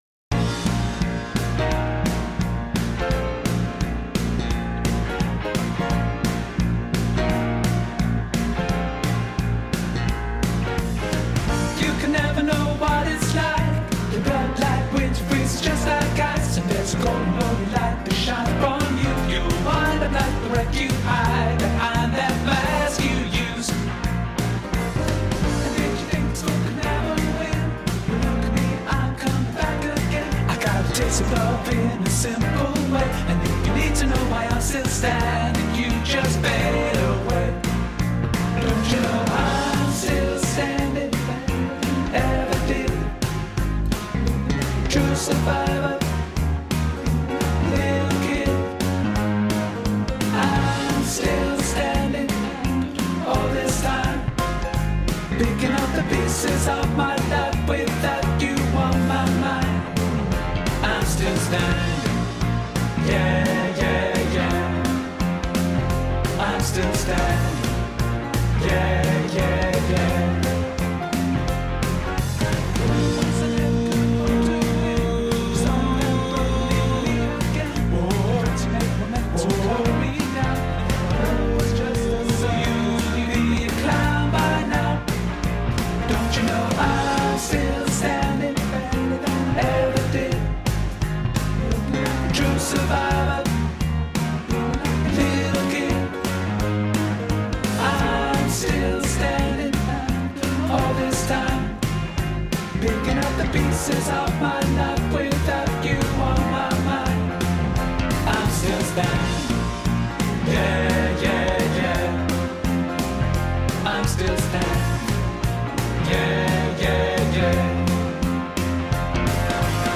I’m Still Standing Tenor Backing Track | Ipswich Hospital Community Choir